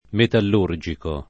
vai all'elenco alfabetico delle voci ingrandisci il carattere 100% rimpicciolisci il carattere stampa invia tramite posta elettronica codividi su Facebook metallurgico [ metall 2 r J iko ] agg. e s. m.; pl. m. -ci — cfr. metallurgo